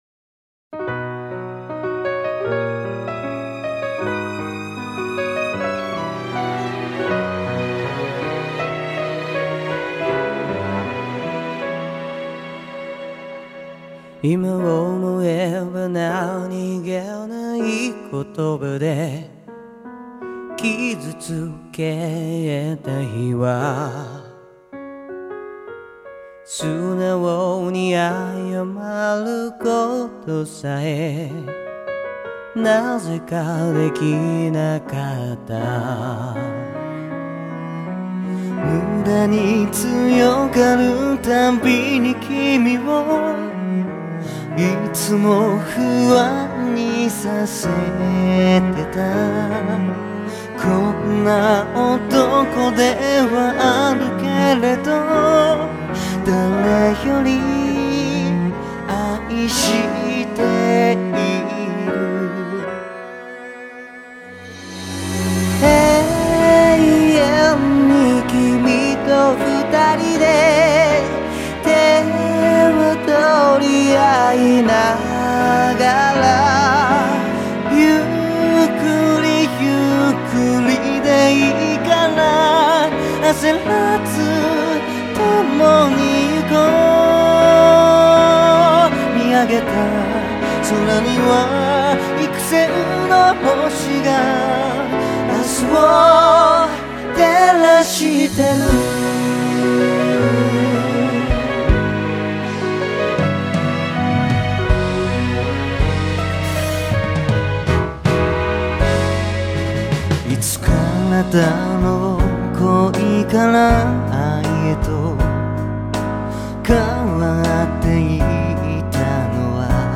정말 이 곡은 뭔가... 깨끗한 눈의 색깔을 하고 있달까.
그런 꾸밈없이 소박하면서도, 또 수줍지만 솔직하면서도 따뜻한 느낌이 나서 너무 좋다.